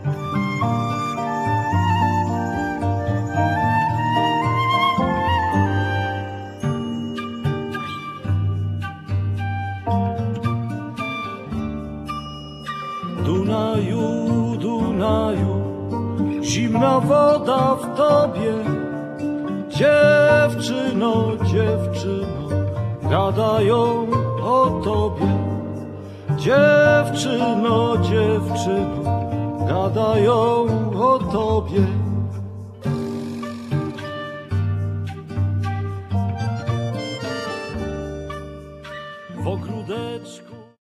wiolonczela, chórki
mandola, dutar, gitara
akordeon